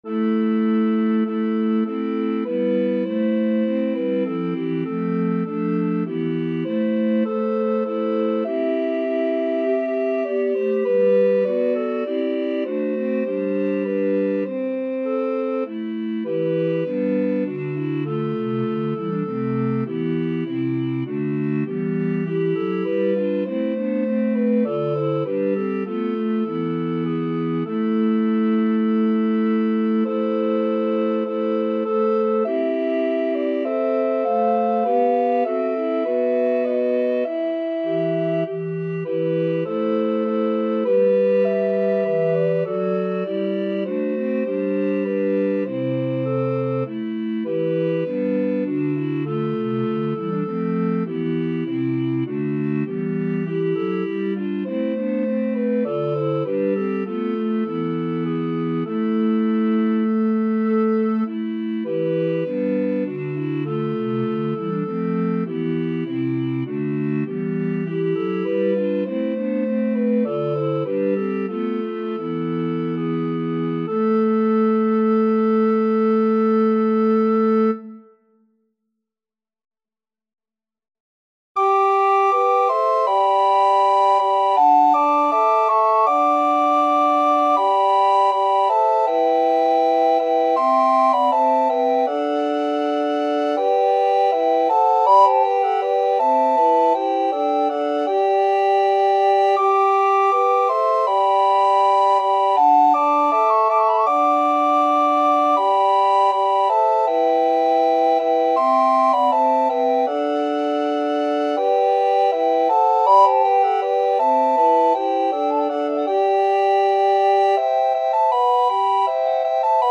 Arrangements of Renaissance, Baroque and Traditional Music for Recorders
These 2 pieces represent "perfect" Renaissance style 4 part chanson writing. The 1st is for TBBGb recorders & the 2nd, for SATB quartet, is highly ornamented in Renaissance style.